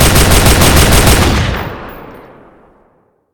gun.wav